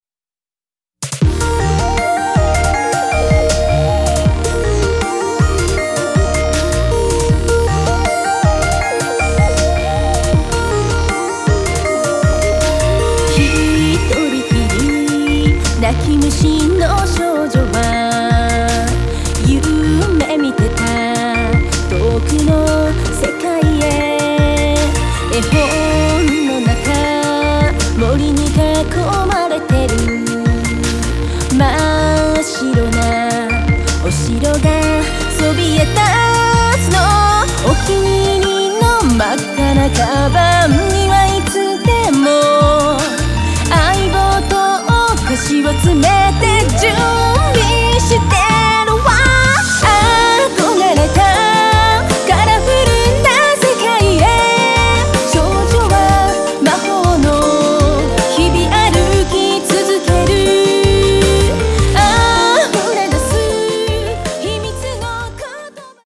クロスフェードデモ